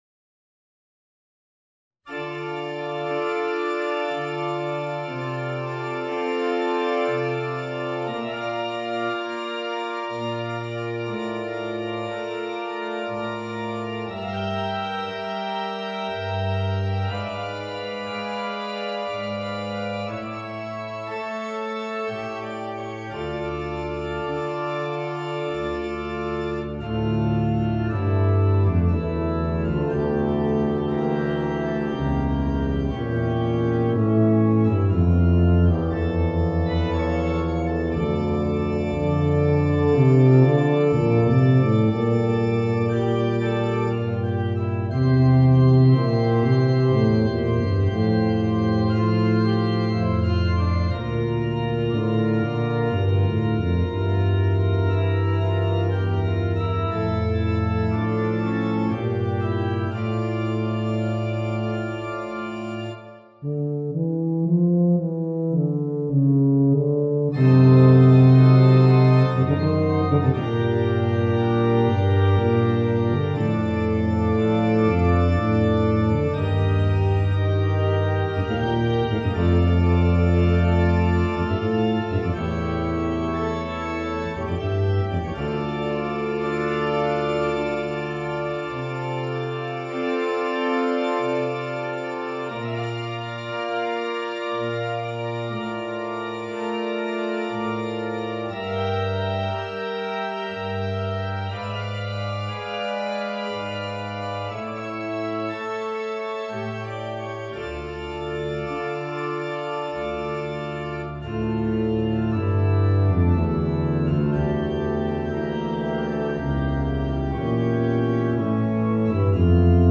Bb Bass and Organ